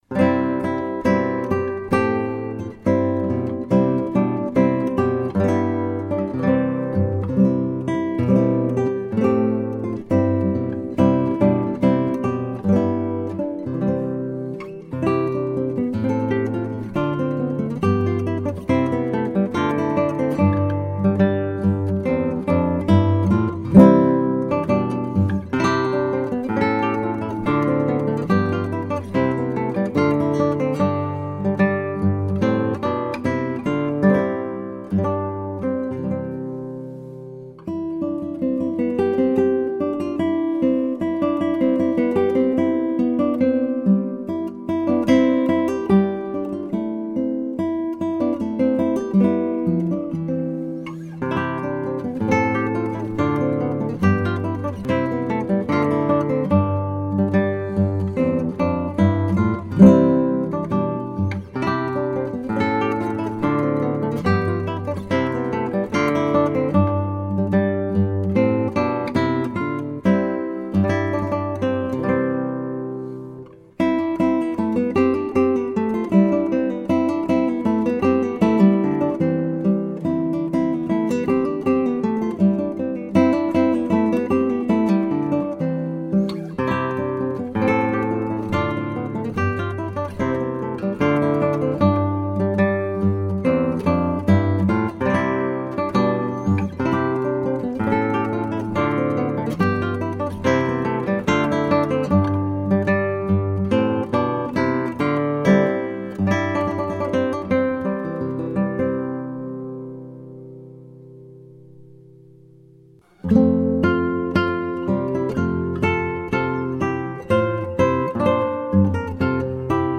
toasty, elegantly merry mood
carols
simple acoustic guitar